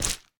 resin_break2.ogg